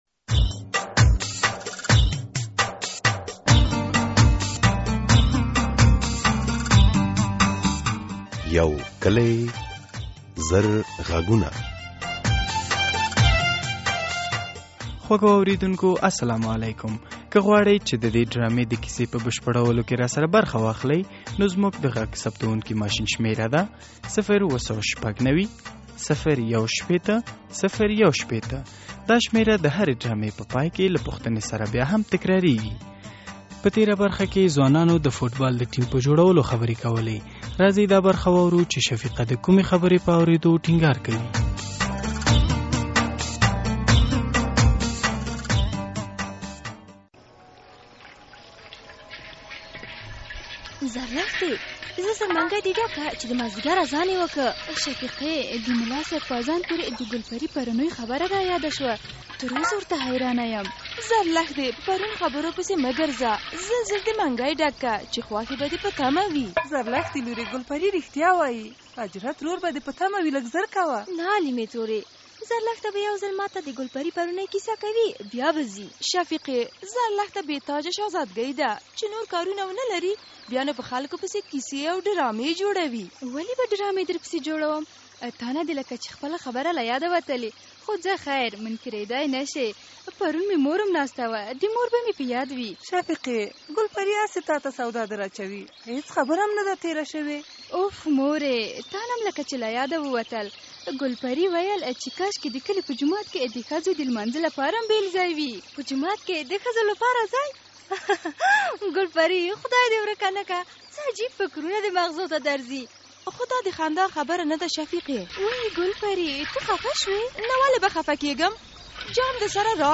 یوکلي او زرغږونه ډرامه هره اونۍ د دوشنبې په ورځ څلور نیمې بجې له ازادي راډیو خپریږي.